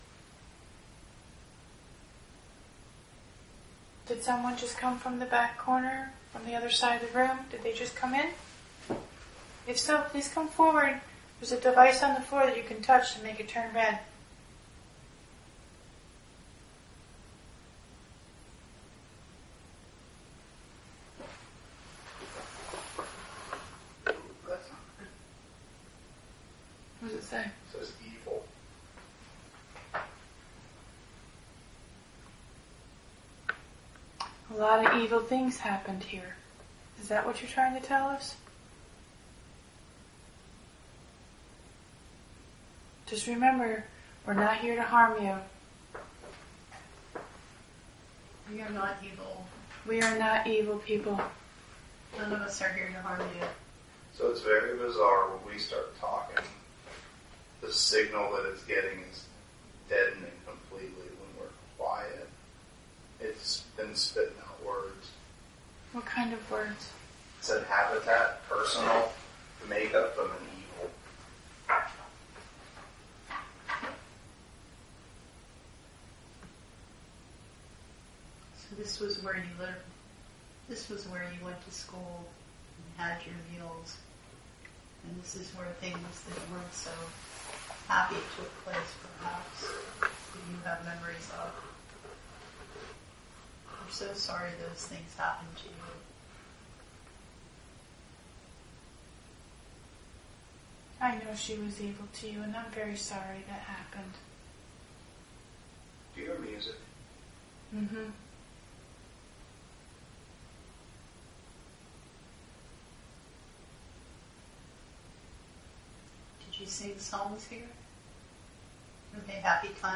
Recorder 3 – Spirit Box:
• A scratching noise was captured at 4:13.